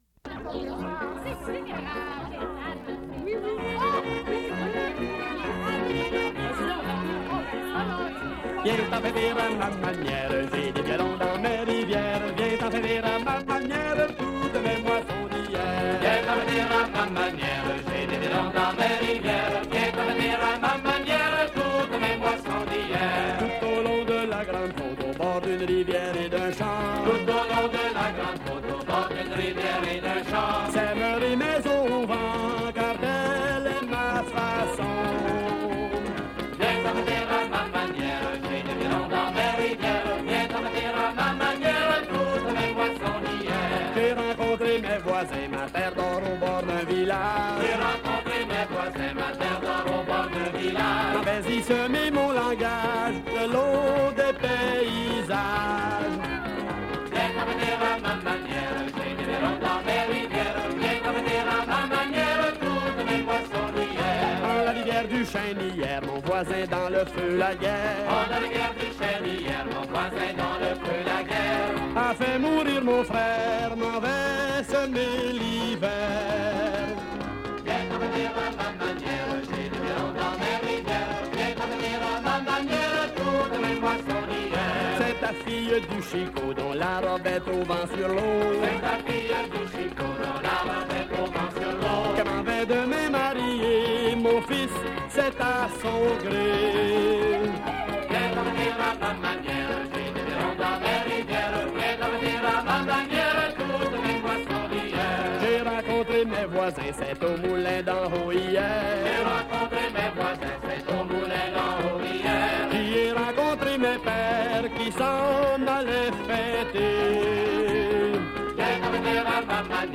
* As this is a 1970’s clip from the Fêtes du Vieux Saint-Eustache archives, the sound quality is limited.
Inspired by traditional Québec folk tunes, it was often heard all over the site.